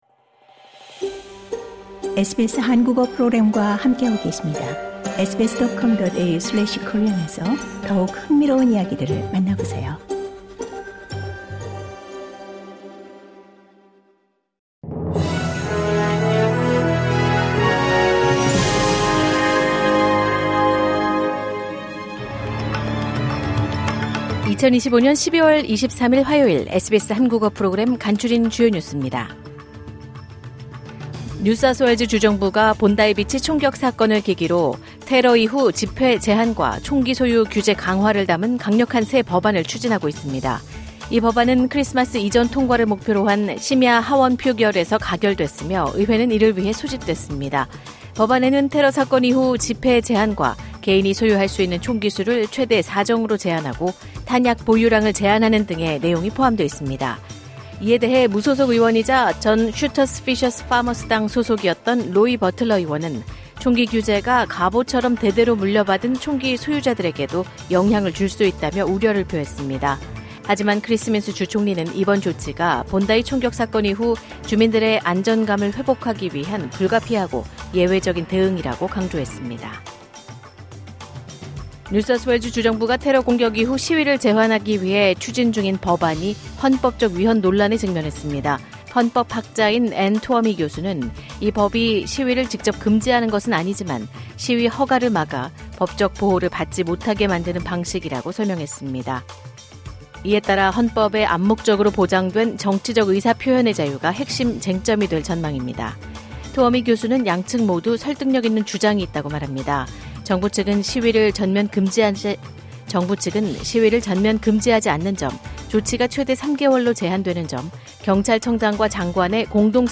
호주 뉴스 3분 브리핑: 2025년 12월 23일 화요일